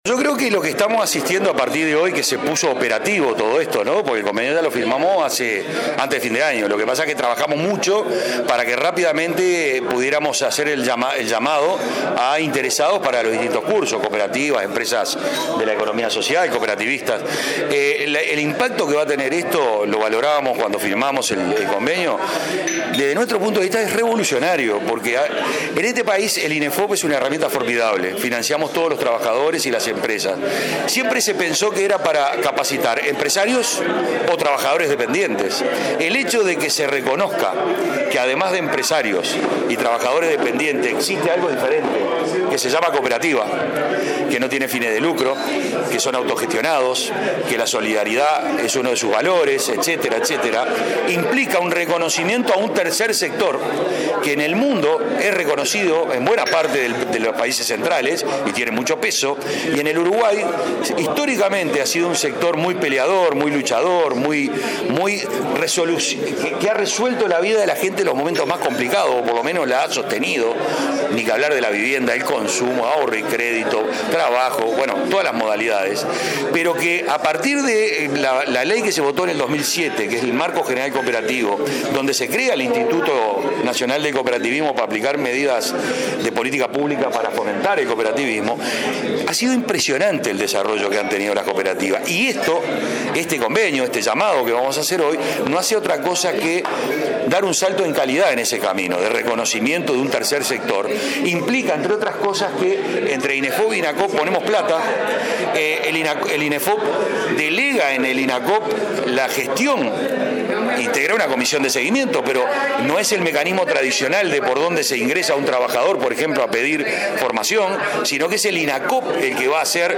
El Instituto Nacional del Cooperativismo, Inefop y la Confederación Uruguaya de Entidades Cooperativas dieron detalles del llamado para el programa de Formación Cooperativa que ofrece formación técnico profesional en cooperativismo y promueve aprendizajes para el fortalecimiento de la economía social. El presidente de Inacoop, Gustavo Bernini, dijo que se invertirán 2,6 millones de dólares por dos años para esa capacitación.